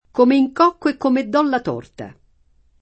torta
torta [ t 0 rta ] s. f.